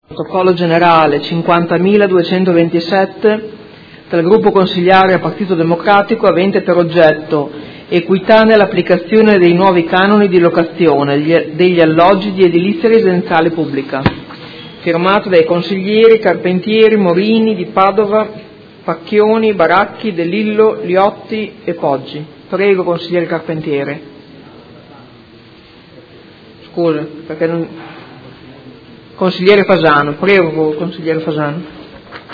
Seduta del 5/04/2018. Comunicazione di presentazione nuovo Ordine del Giorno in corso di seduta collegato a proposta di deliberazione: Approvazione Regolamento per la definizione delle modalità di calcolo e di applicazione dei canoni di locazione degli alloggi di edilizia residenziale pubblica con decorrenza 1 ottobre 2017